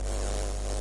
舔电缆" 噪音拍
描述：受池田亮司的启发，我录下了我用手指触摸和舔舐连接到我的电脑线路输入口的电缆的声音。基本上是不同的fffffff，trrrrrr，和glllllll的最小噪音的声音...
Tag: 电缆 选RIC 电子 机械 噪声 信号